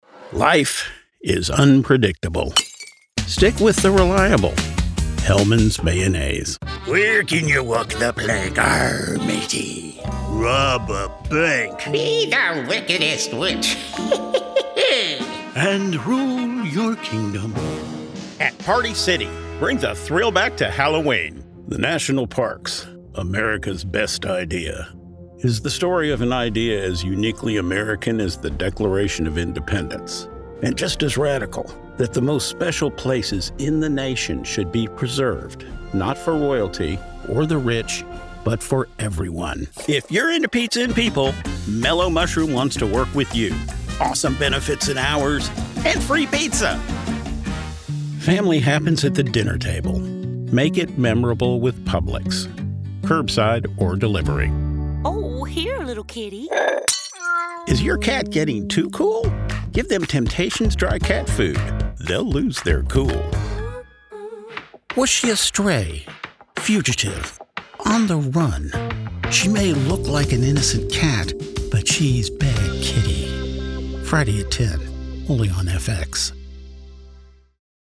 Why yes -- I do have a demo!